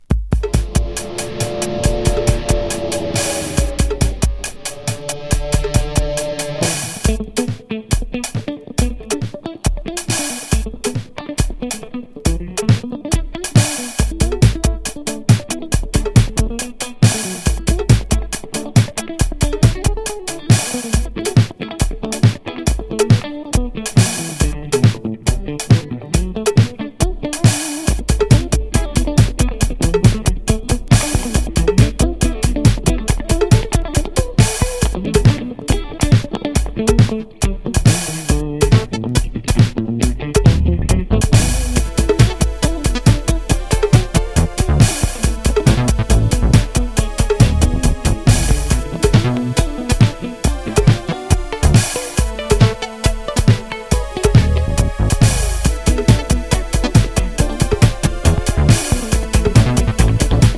レフティ・ディスコ・ロッキン！